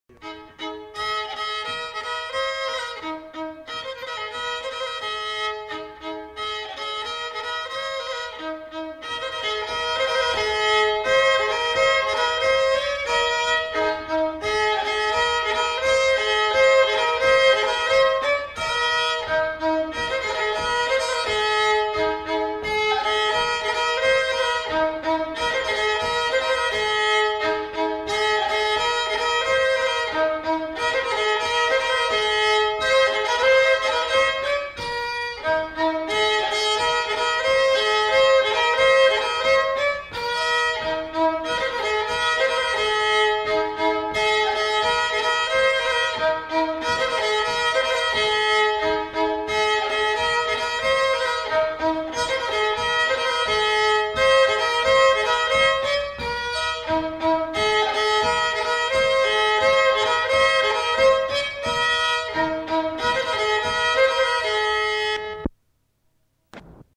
Aire culturelle : Béarn
Lieu : Bielle
Genre : morceau instrumental
Instrument de musique : violon
Danse : saut béarnais
Notes consultables : Le joueur de violon n'est pas identifié.